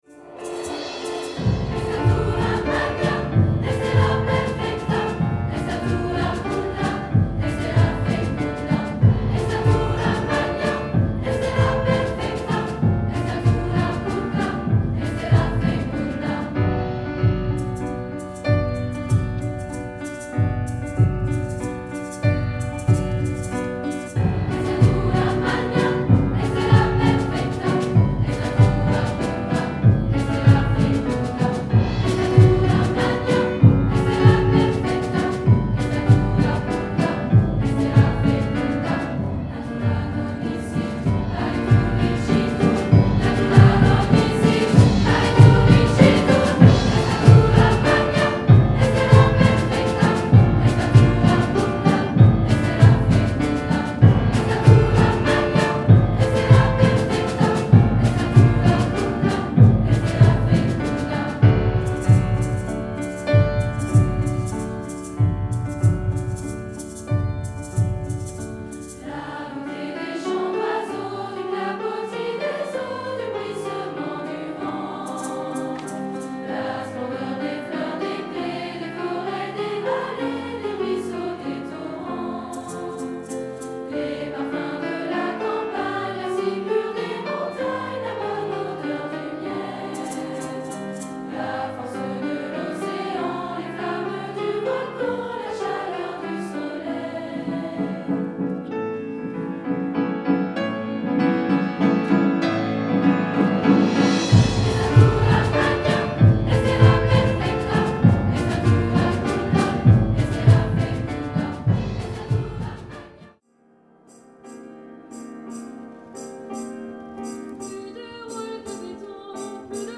Il s’agit d’une cantate profane en français